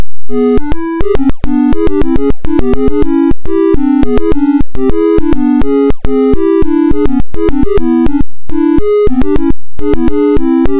diese (ogg, 360kB), bei der die Akkord-Zuordnung dem Draw-Algorithmus folgt.